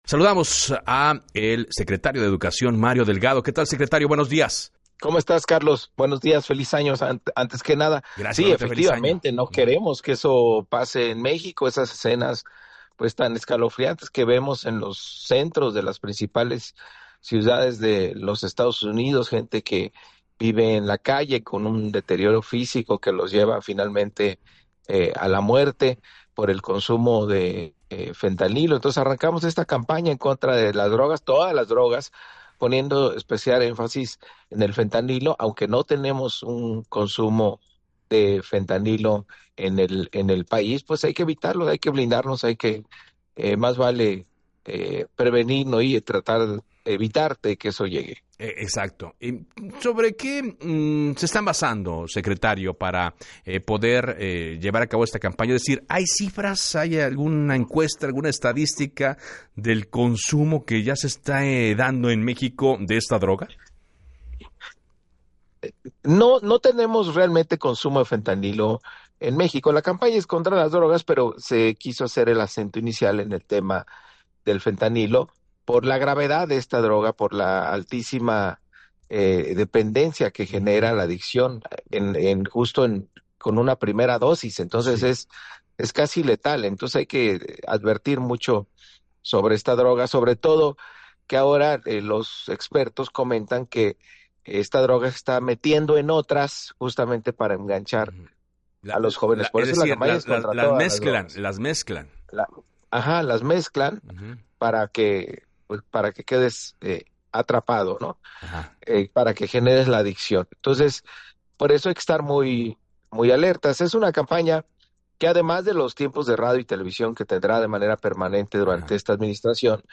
En entrevista para “Así las Cosas”